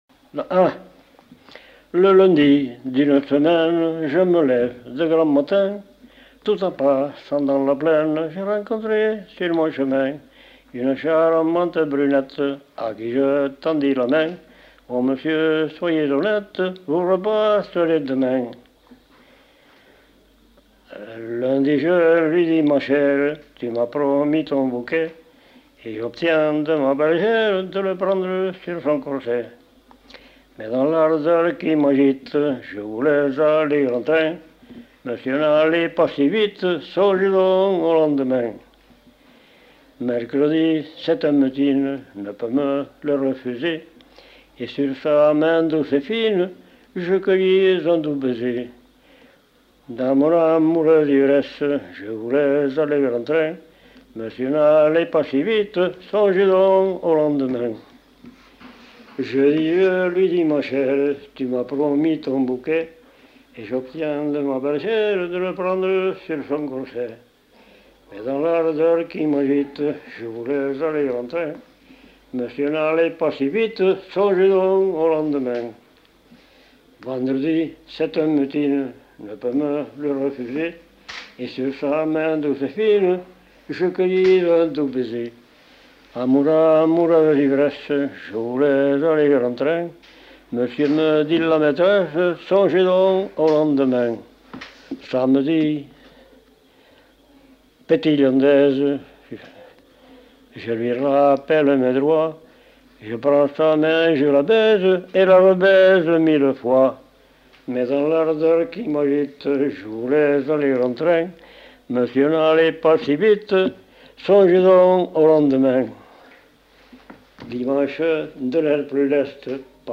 Aire culturelle : Lomagne
Genre : chant
Effectif : 1
Type de voix : voix d'homme
Production du son : chanté
Notes consultables : Chante le début d'un chant à danser à la fin de la séquence.